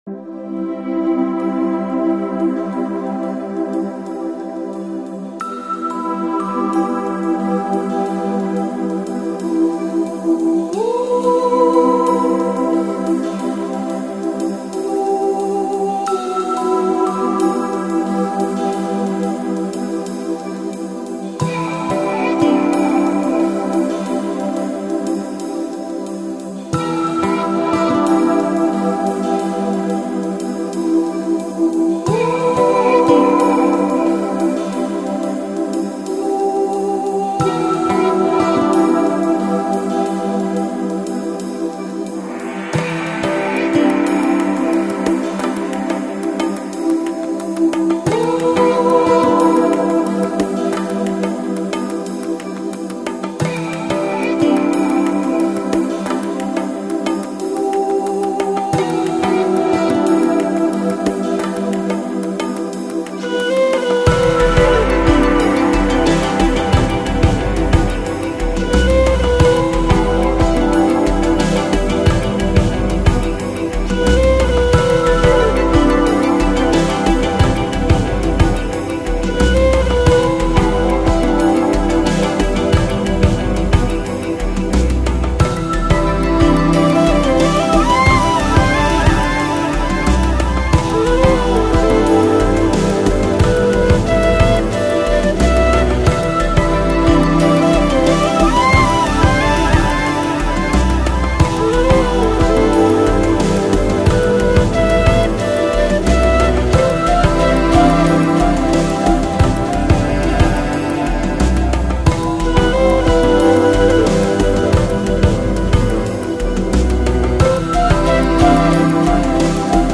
Electronic, Lounge, Chill Out, Downtempo, Ambient